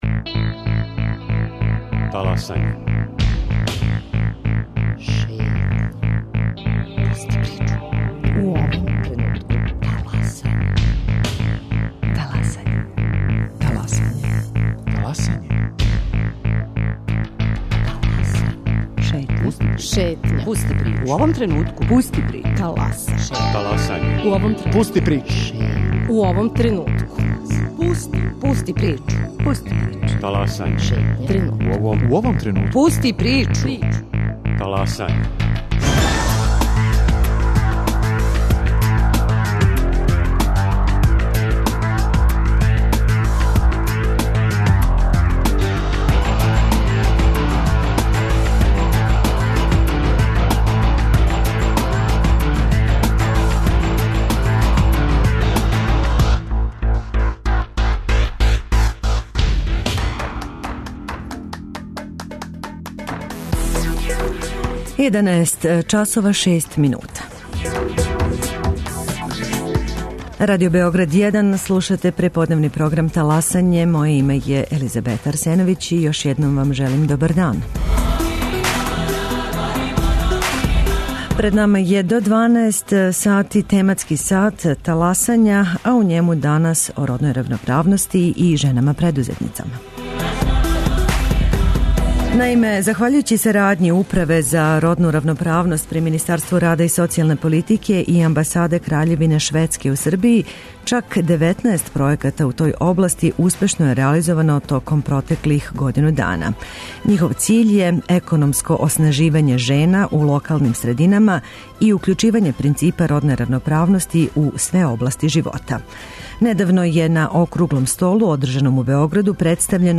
Наша гошћа биће директорка Управе за родну равноправност, Наталија Мићуновић.